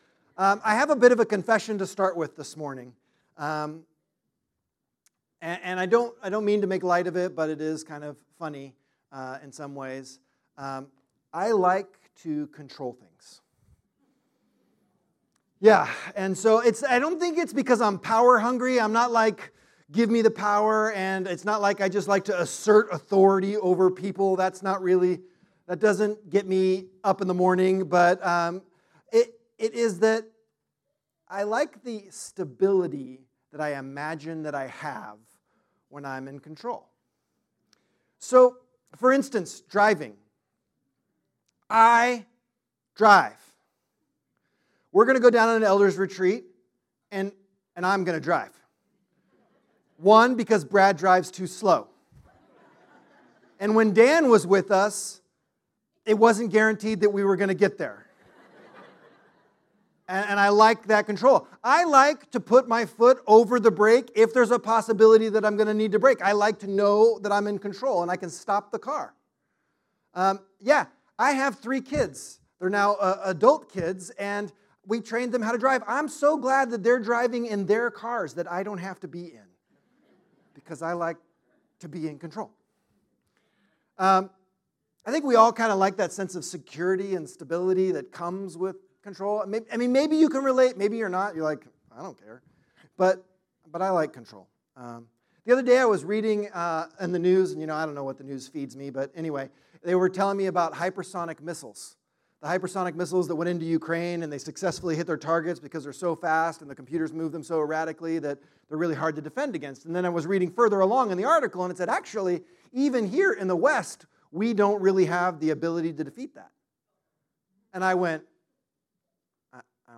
Type: Sermons Book